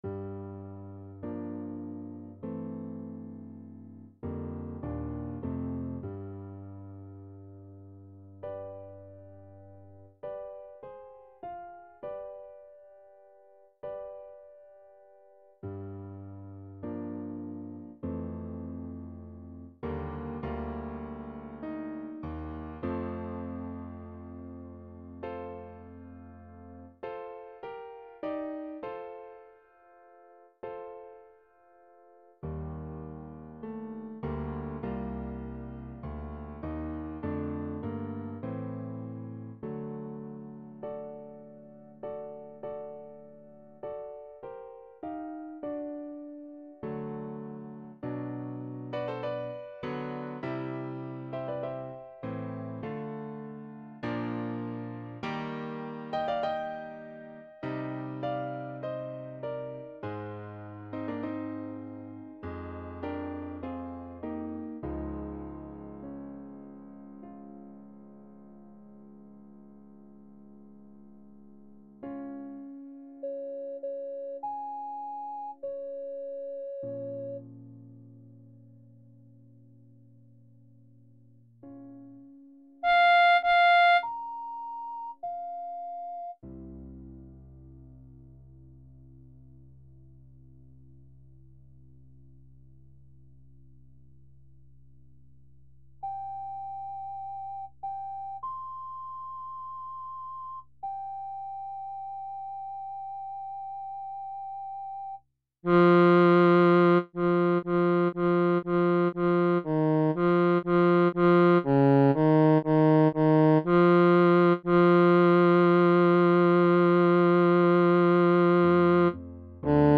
ベース用（他パートなし）